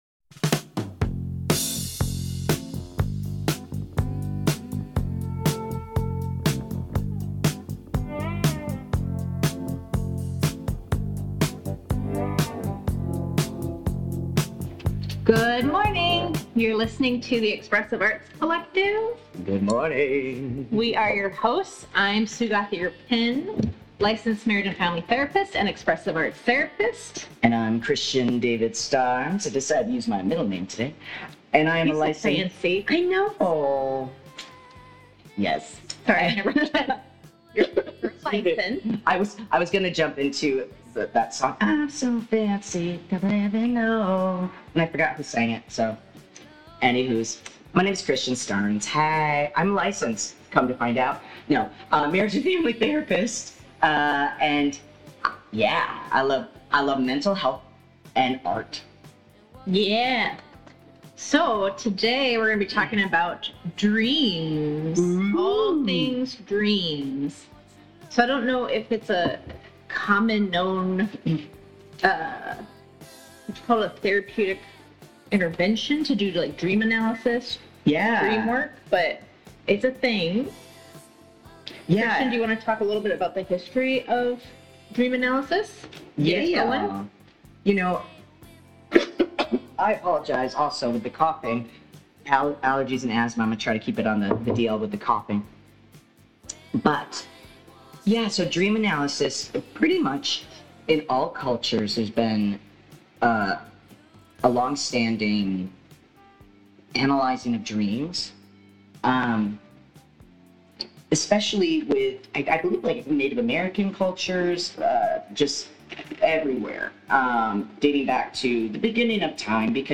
This episode aired live on CityHeART Radio on July 26 at 10:30am.